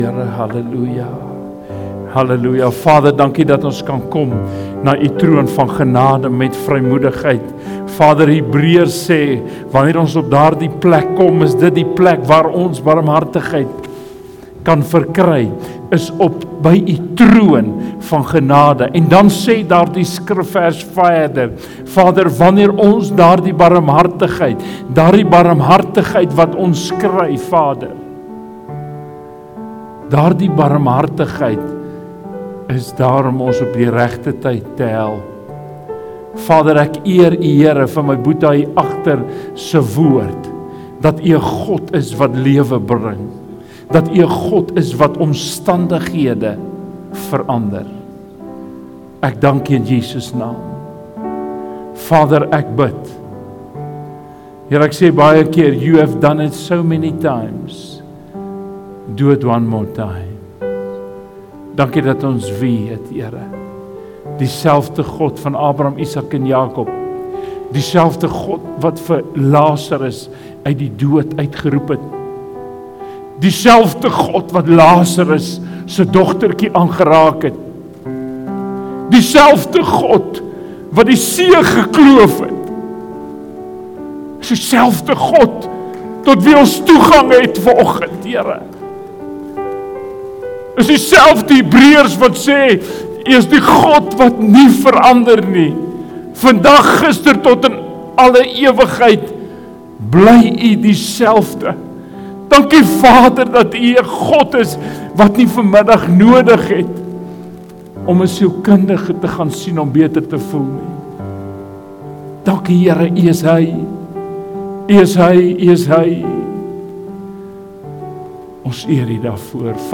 Gas prediker